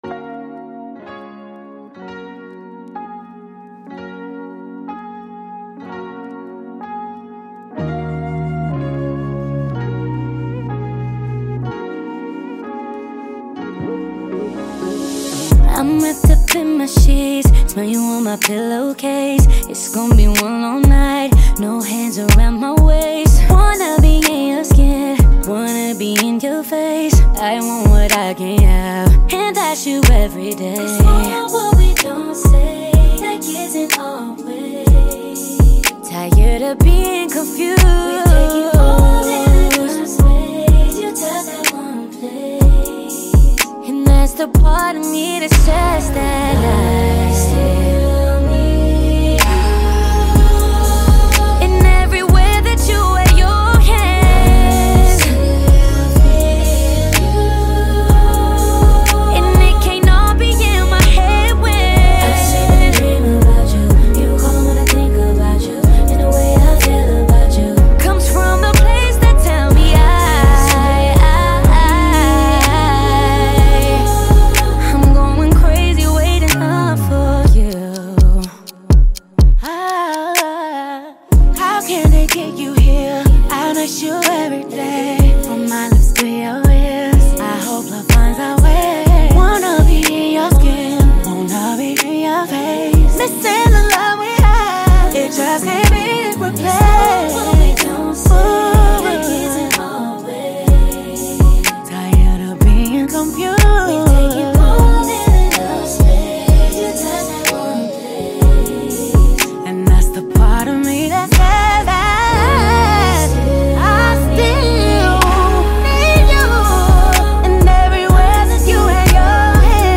combining strong vocals